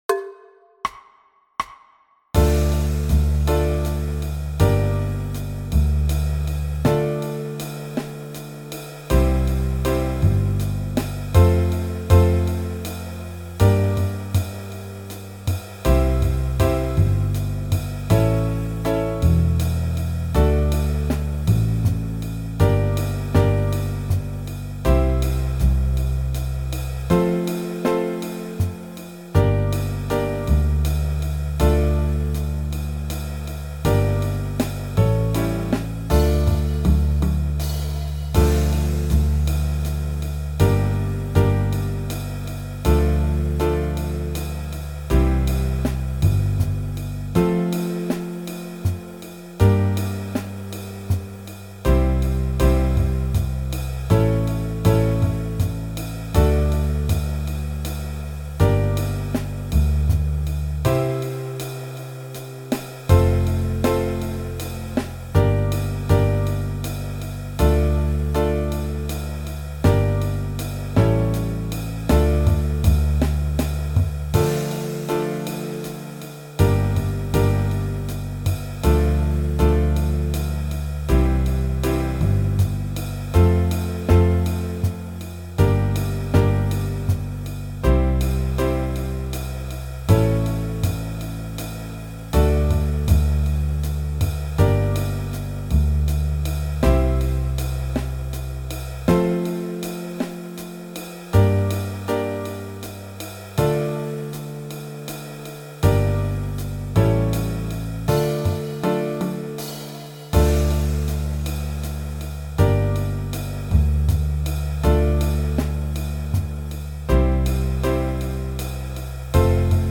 Play-A-Longs
in 70, 80, 90, 100, 110 und 120 bpm